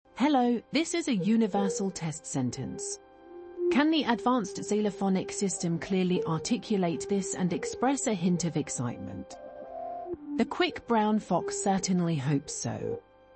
Open-Source-TTS-Gallary